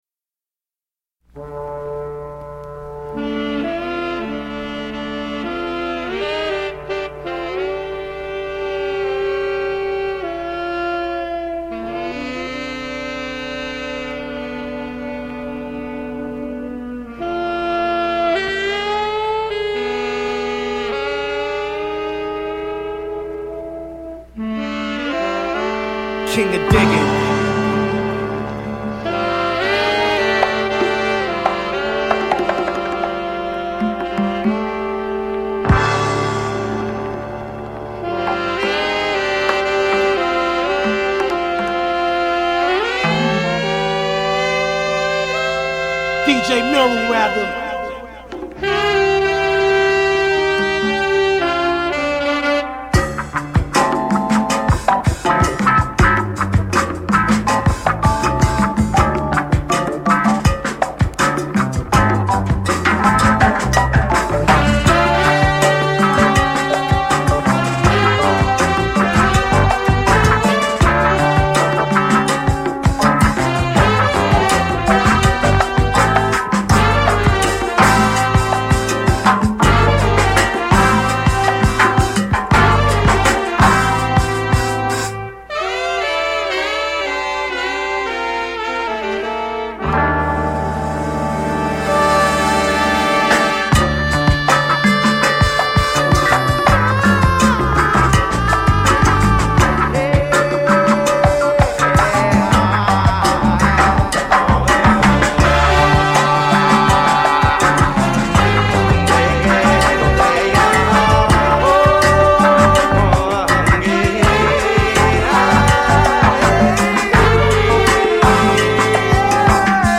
※試聴はダイジェストです。